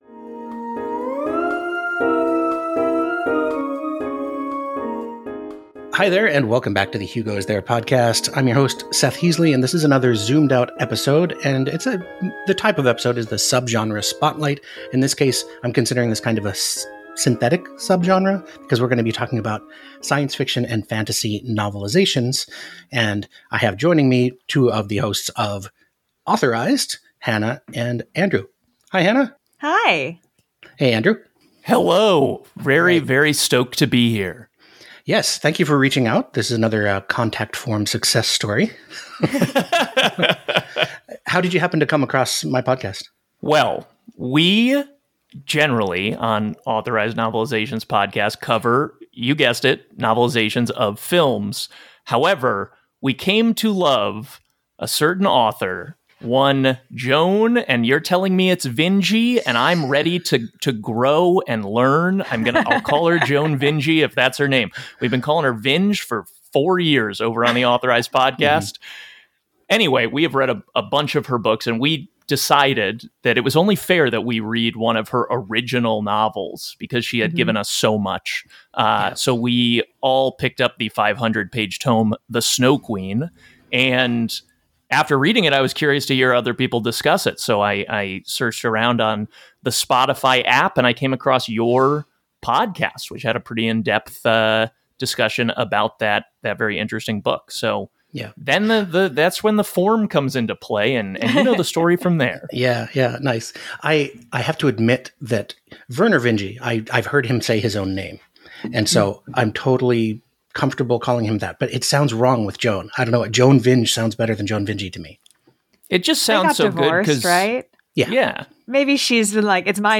Don’t forget to stick around for the quiz at the end of the podcast!
Subgenre Spotlight episode